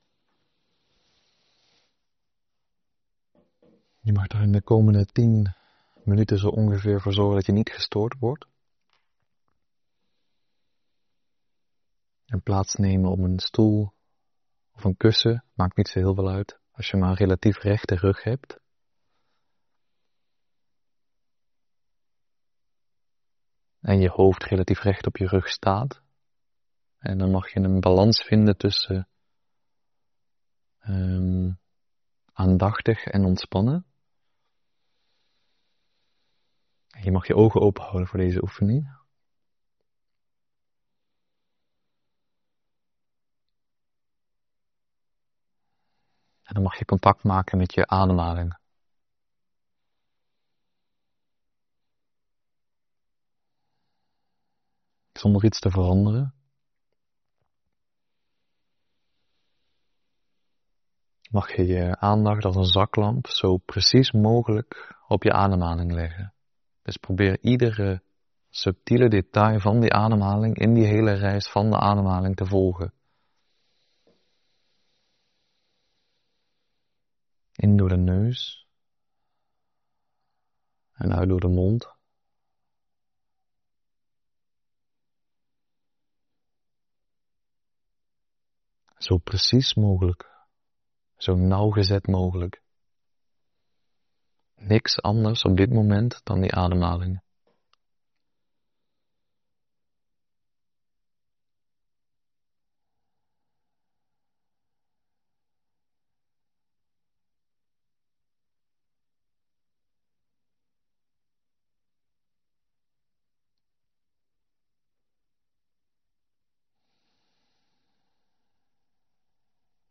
Meditatie: basismeditatie oordeelloos ontvangen. Ik neem je mee in een aantal stappen voor meer rust en ruimte.